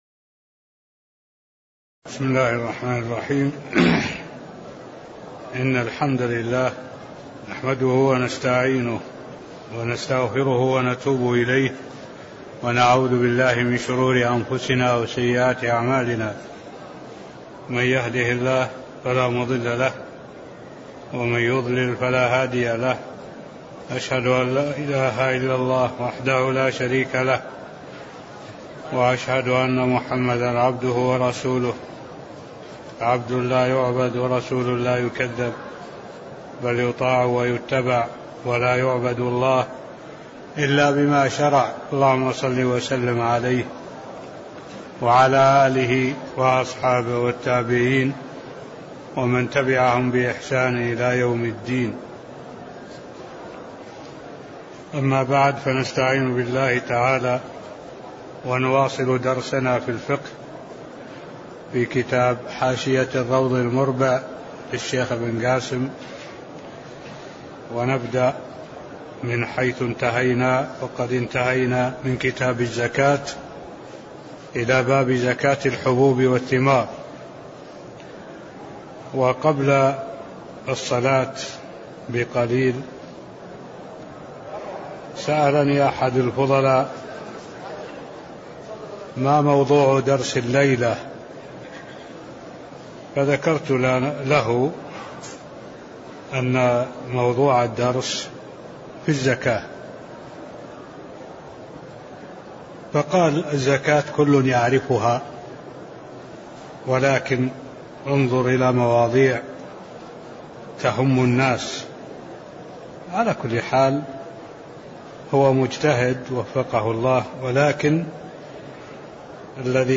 تاريخ النشر ١٥ ربيع الثاني ١٤٢٩ هـ المكان: المسجد النبوي الشيخ: معالي الشيخ الدكتور صالح بن عبد الله العبود معالي الشيخ الدكتور صالح بن عبد الله العبود باب زكاة الحبوب والثمار (004)